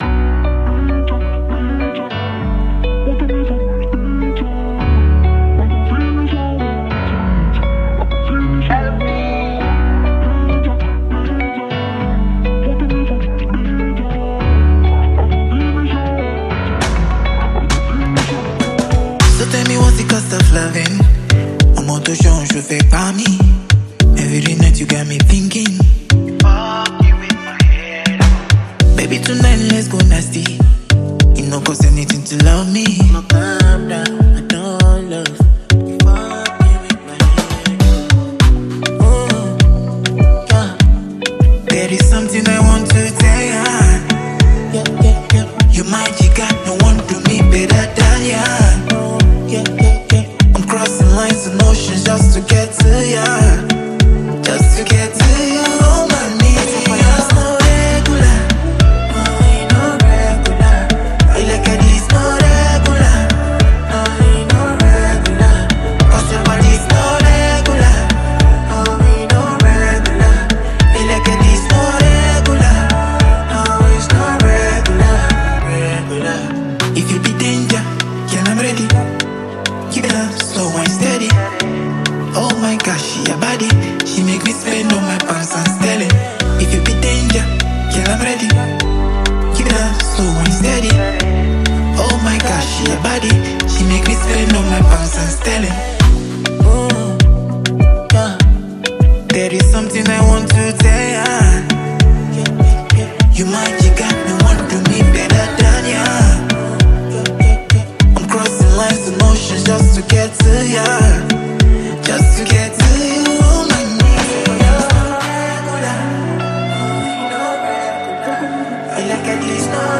With its infectious rhythm and polished production